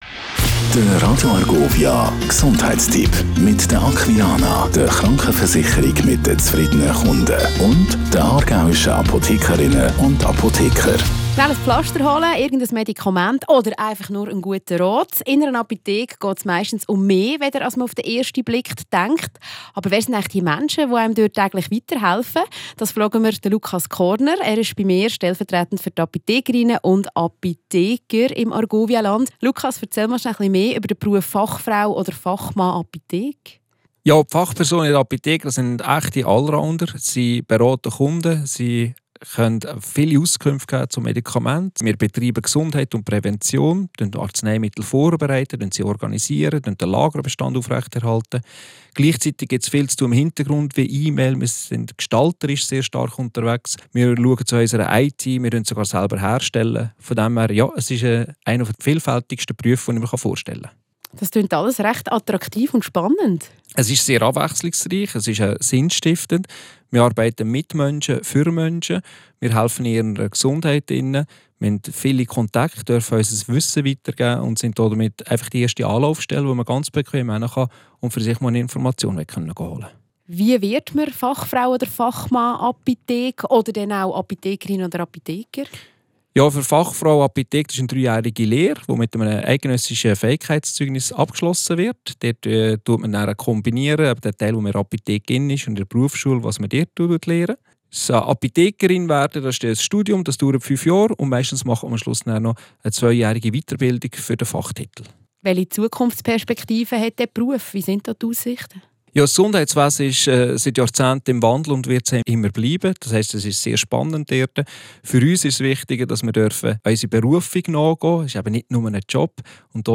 The Aargau Pharmacists Association regularly publishes health tips in the form of short radio reports.